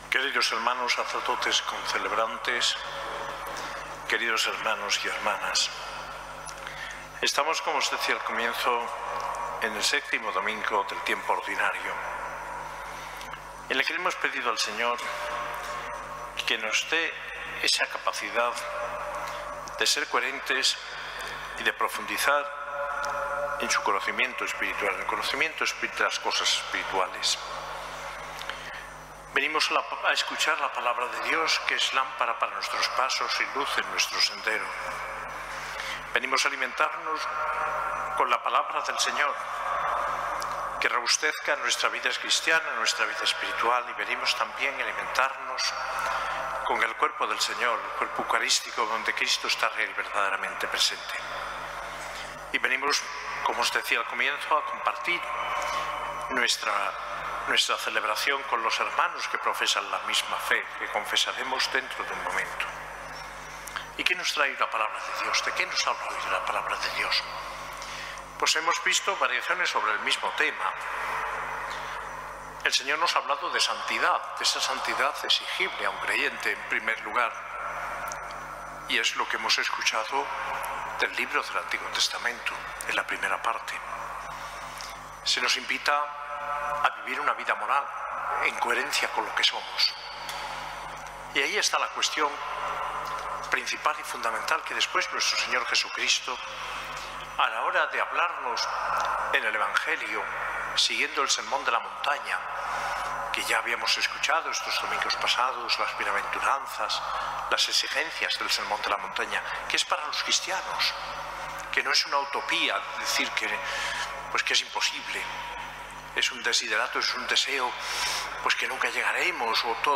Homilía del arzobispo D. José María, en la Eucaristía en el VII Domingo del Tiempo Ordinario, celebrada en la catedral el 19 de febrero de 2023.